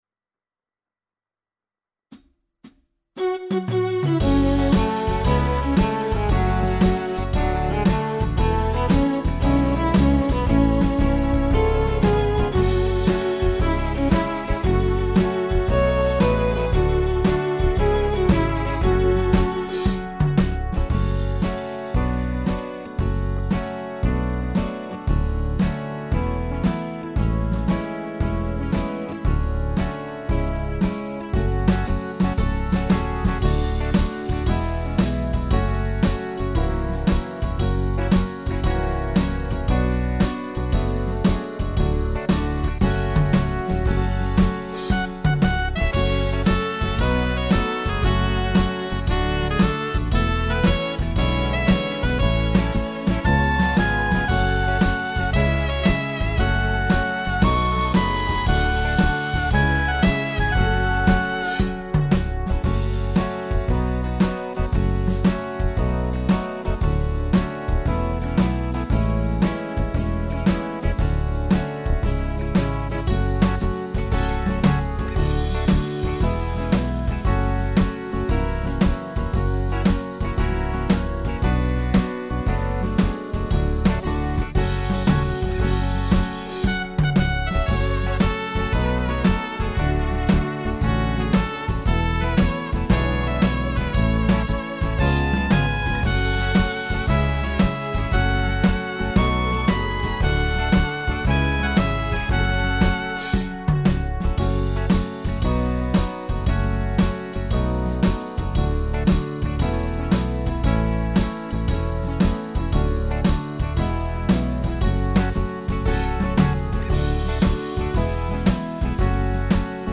•   Beat  02.